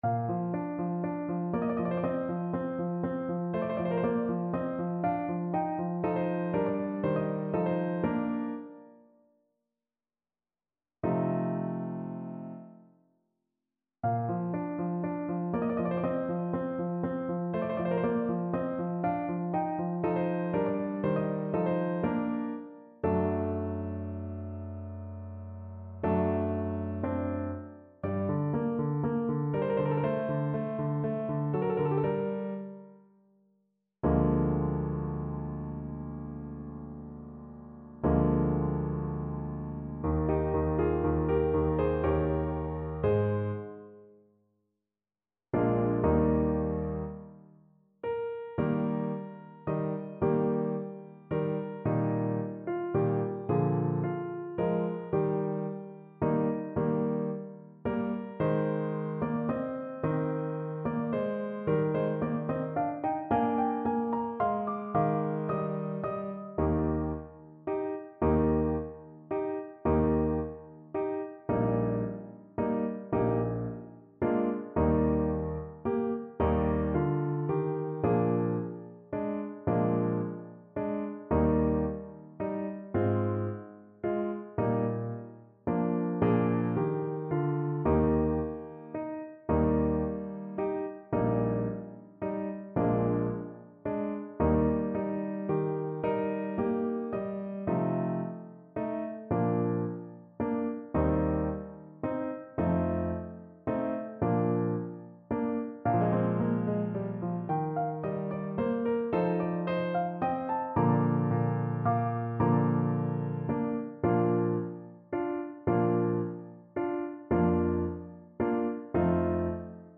Classical Mozart, Wolfgang Amadeus Deh Vieni, Non Tardar from The Marriage of Figaro Trombone version
Play (or use space bar on your keyboard) Pause Music Playalong - Piano Accompaniment Playalong Band Accompaniment not yet available transpose reset tempo print settings full screen
Trombone
Bb major (Sounding Pitch) (View more Bb major Music for Trombone )
Allegro vivace assai (View more music marked Allegro)
Classical (View more Classical Trombone Music)